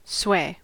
Ääntäminen
US : IPA : [sweɪ]